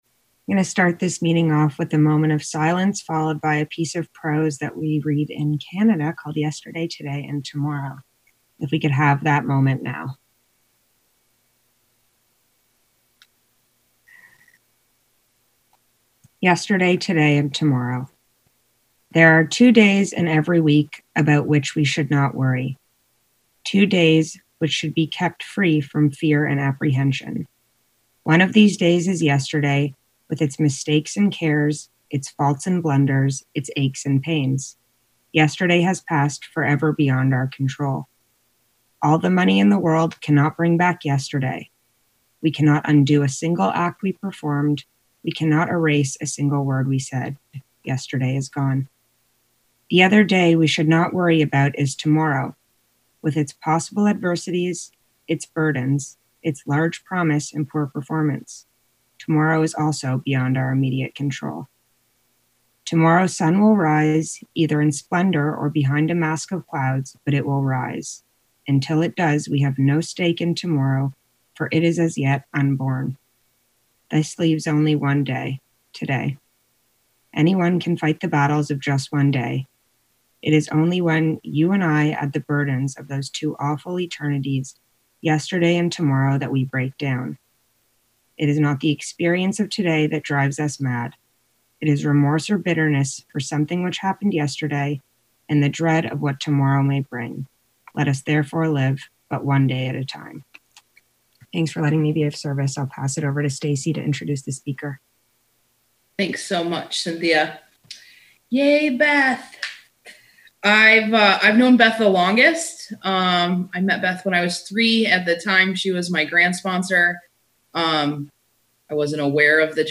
Woodstock Conference Young People In AA Sterling Heights MI